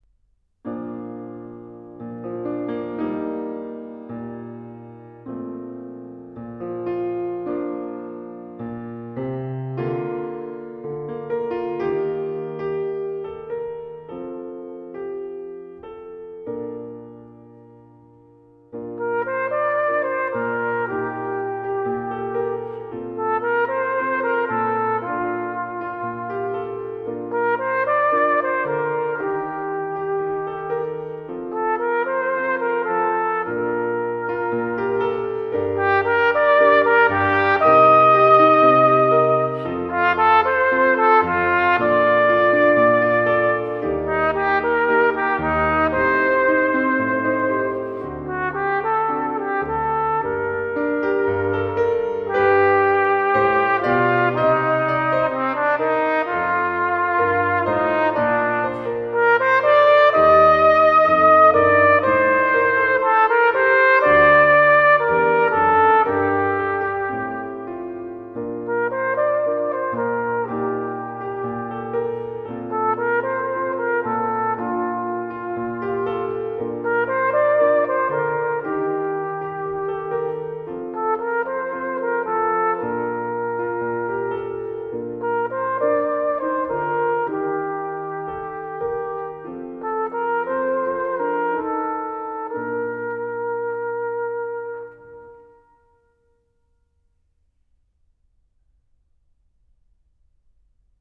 Gattung: Trompete und Klavier inkl.